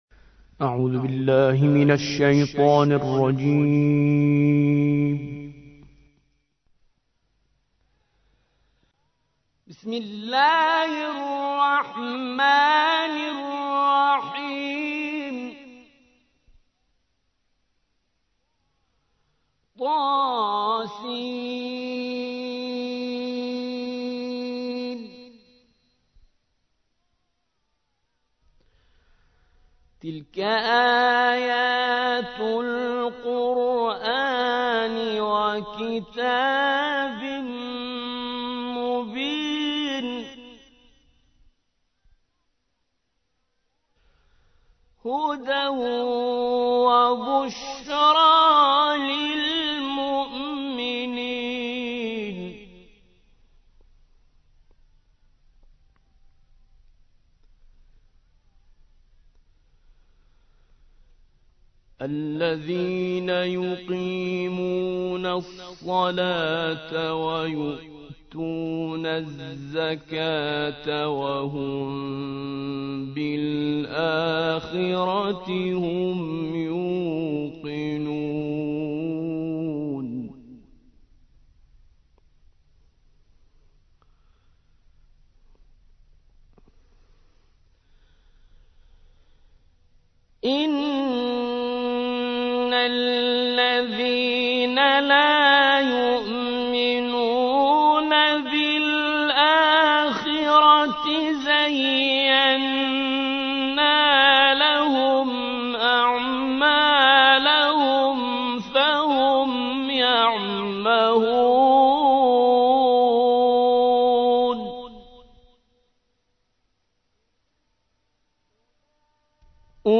27. سورة النمل / القارئ